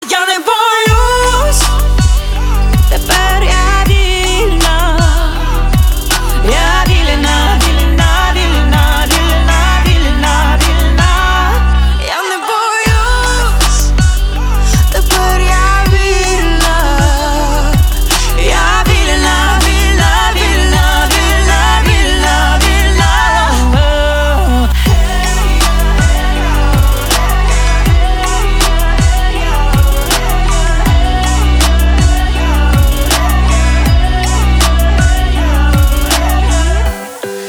• Качество: 320, Stereo
поп
женский вокал
мотивирующие
спокойные
дуэт
красивый женский голос